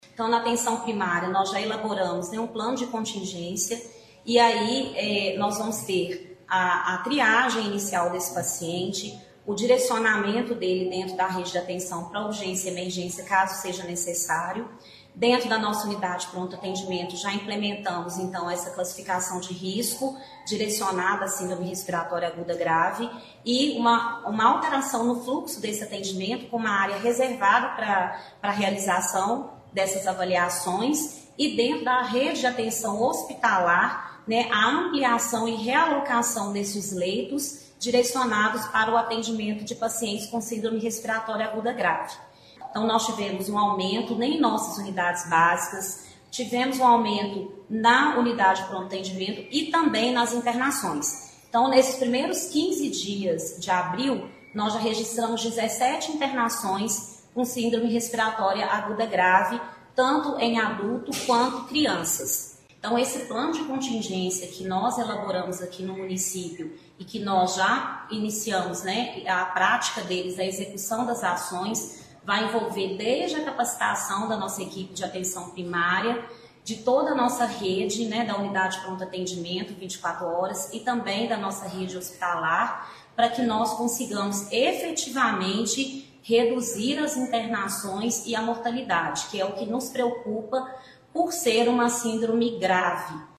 A secretária municipal de Saúde, Paula Botelho, detalha as ações que deverão ser tomadas, com o objetivo de evitar a sobrecarga do sistema e garantir assistência à população, principalmente nos casos considerados mais graves.